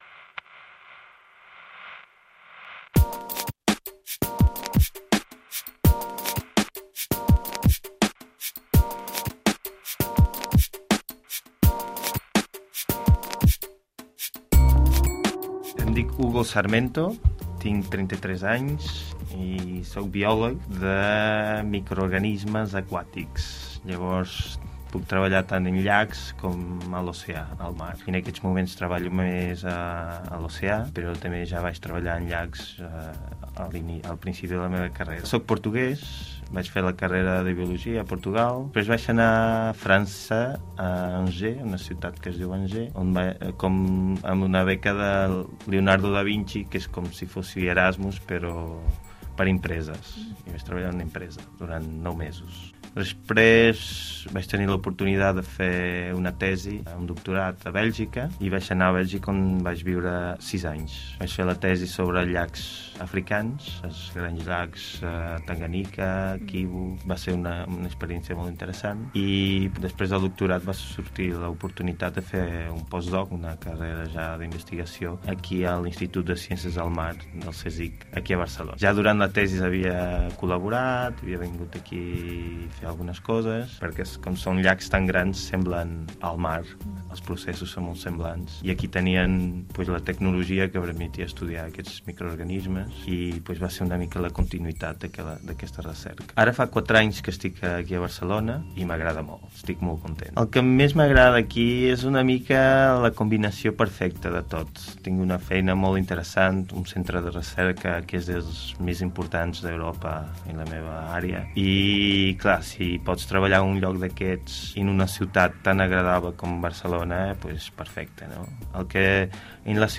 Interview (catalan) "Maneres de viure" COM-Radio - Barcelona (January 2012)